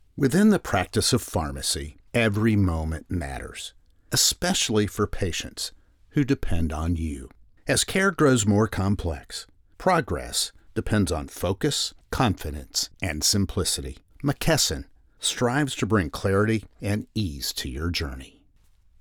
Never any Artificial Voices used, unlike other sites.
E=learning, Corporate & Industrial Voice Overs
Adult (30-50) | Older Sound (50+)